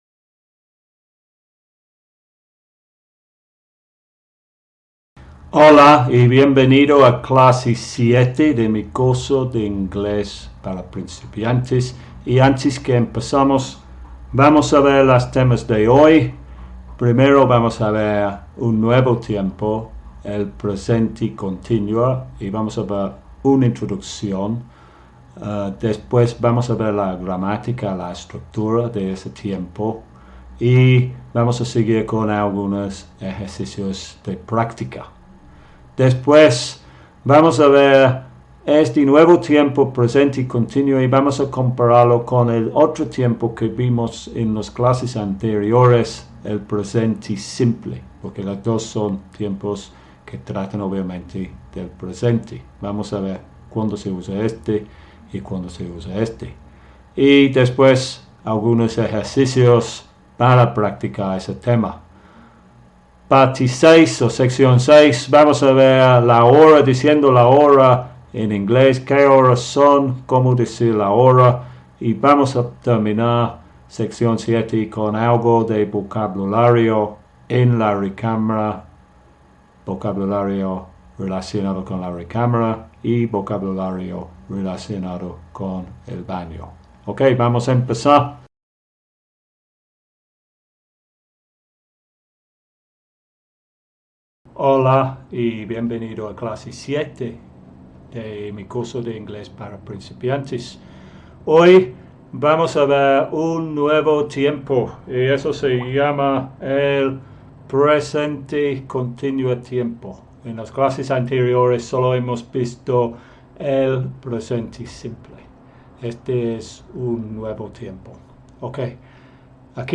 Curso de inglés para principiantes (A1 CEF): clase 07